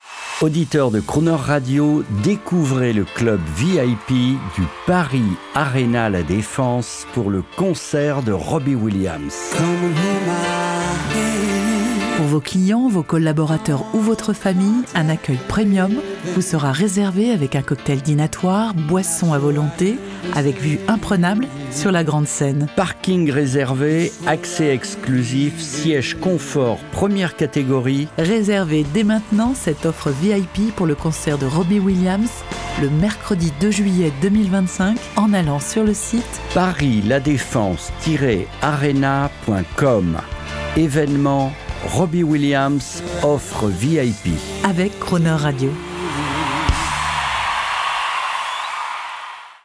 Spot audio | Gérard Drouot Productions - La Defense Accor Arena (Robbie Williams)
PUBLICITE-PARIS-LA-DEFENSE-ARENA-ROBBIE-WILLIAMS.mp3